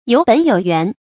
有本有原 注音： ㄧㄡˇ ㄅㄣˇ ㄧㄡˇ ㄧㄨㄢˊ 讀音讀法： 意思解釋： 亦作「有本有源」。